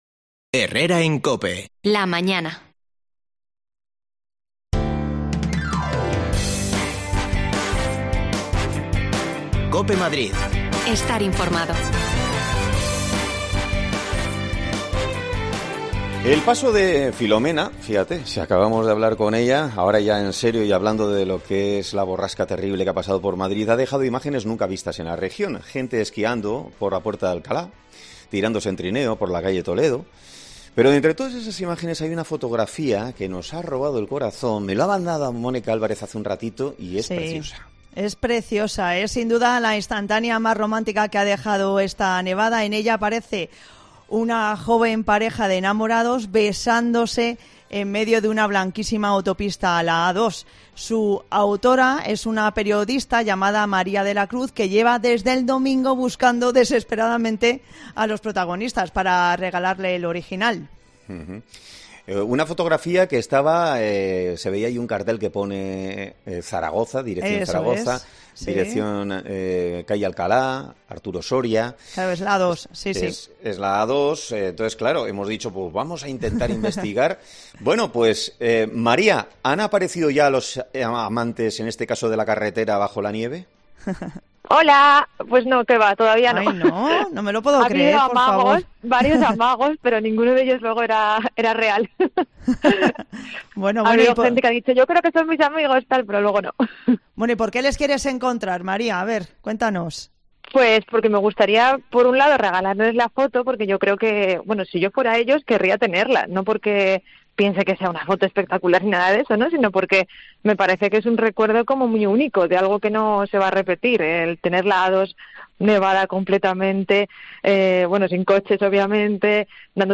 Está buscando a la pareja de enamorados que fotografió este sabado pasado en una autopista A2 totalmente nevada. Escucha su entrevista por si eres tú